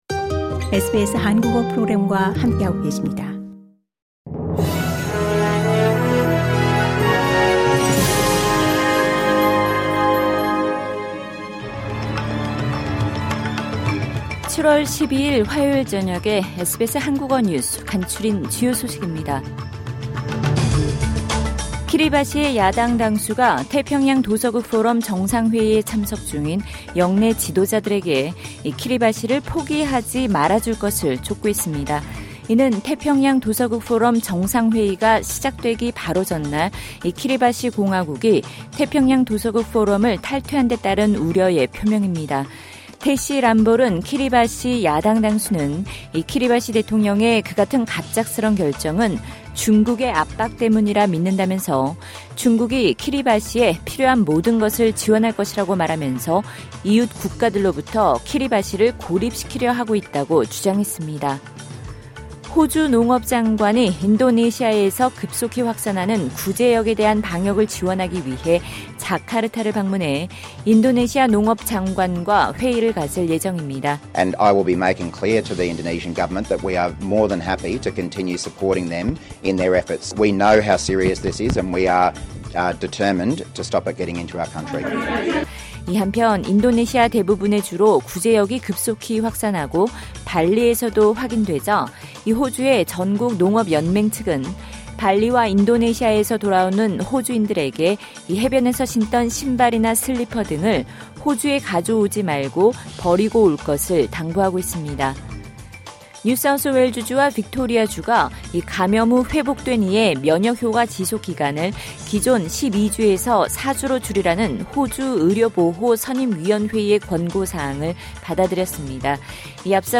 SBS 한국어 저녁 뉴스: 2022년 7월 12일 화요일